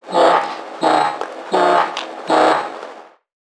NPC_Creatures_Vocalisations_Infected [76].wav